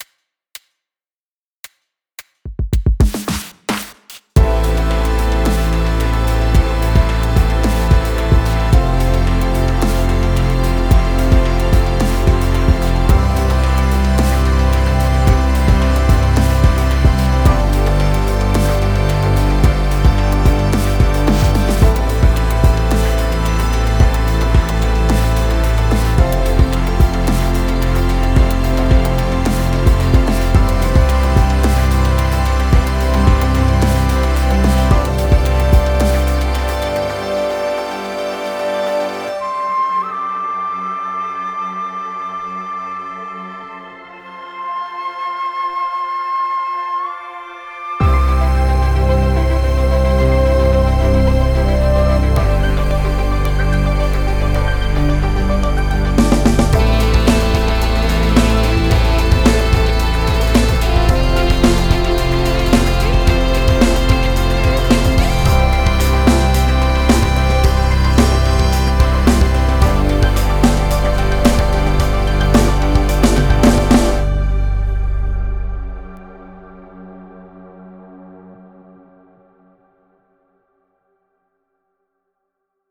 Никакой "статики" не услышал, приятный аранж, кое-где есть нестроевич небольшой, подсвести бы еще а то все в кашу сливается. только недавно обрел мониторы нормальные, еще отделываю комнату, поэтому сведение всегда успеется)